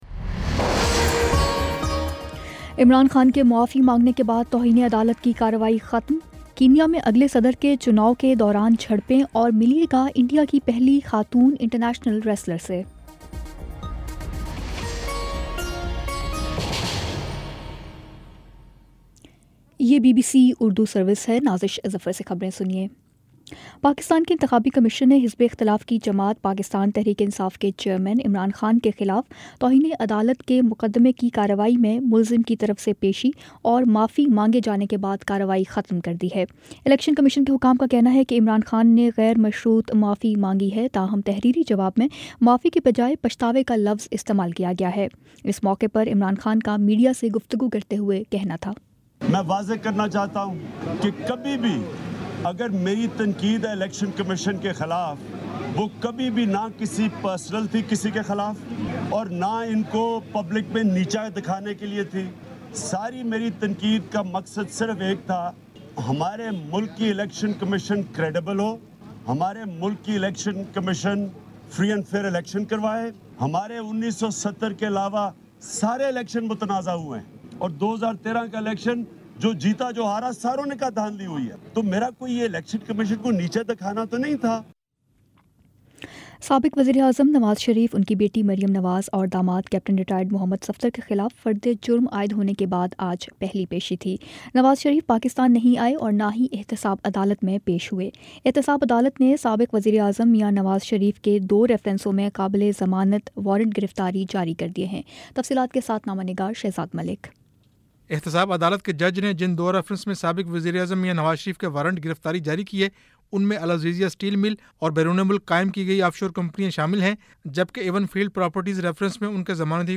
اکتوبر 26 : شام پانچ بجے کا نیوز بُلیٹن